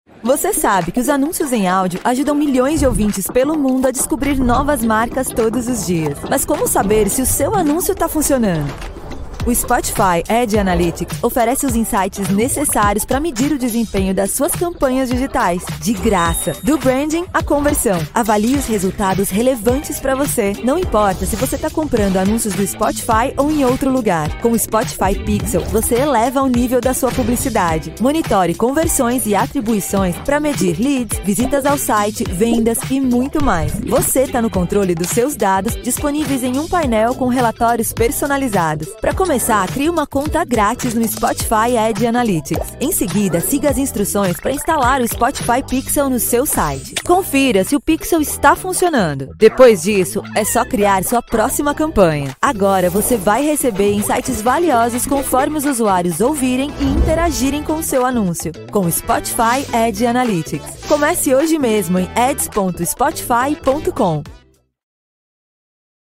Female
Versatile and charismatic voice. Natural style, which can be interpreted in a lively and energetic way to capture the audience's attention, or in a smooth, pleasant and interesting way to keep listeners connected and attentive.
Pro Home Studio- Acoustically treated WhisperRoom, Neumann TLM102 mic, Scarlett Solo Focusrite Interface and Pro tools daw
Explainer Videos
Young, Charisma And Personalit